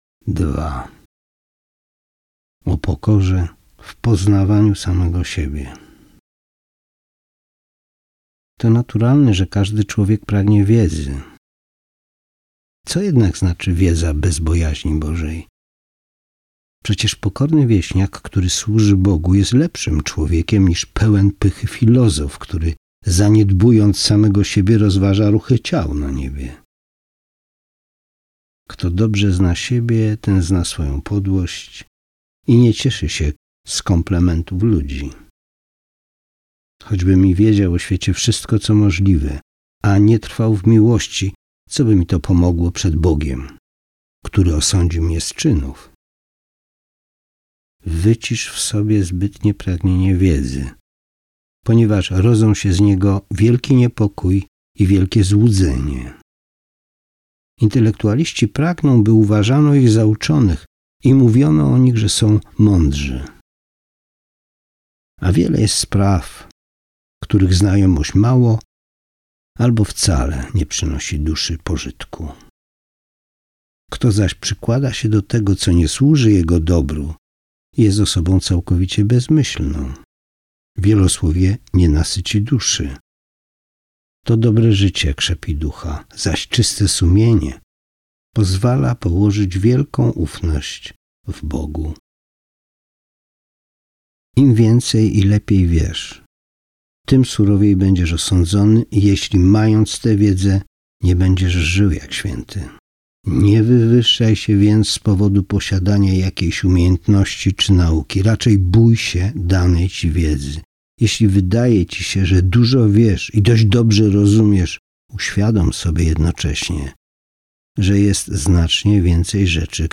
Audiobook "O naśladowaniu Chrystusa" to przewodnik duchowy, który pomoże Ci rozwijać pokorę i miłość na drodze do bliższej relacji z Chrystusem.